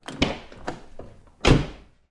打开并关上V W帕萨特车门
描述：这是一扇大众帕萨特的车门被打开和砰砰作响。在一个车库（6 x 6米）内录制的。这个样本中有相当多的自然混响。
Tag: 汽车门 门大满贯 现场记录 VW-帕萨特